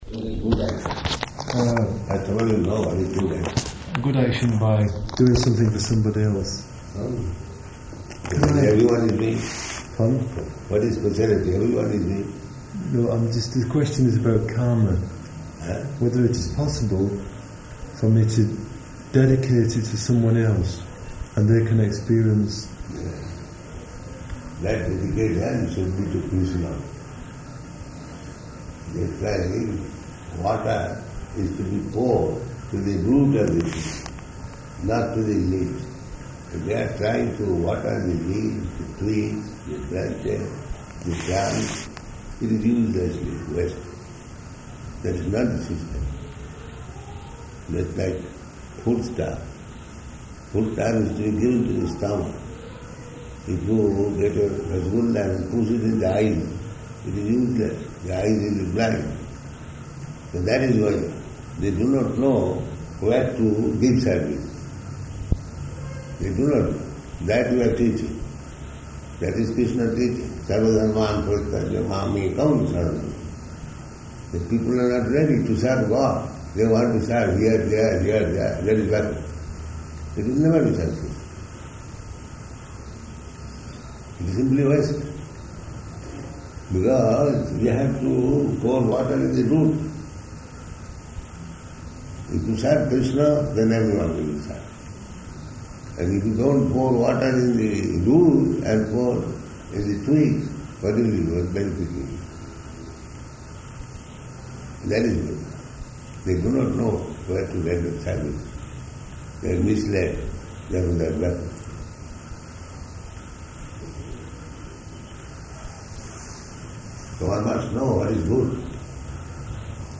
Room Conversation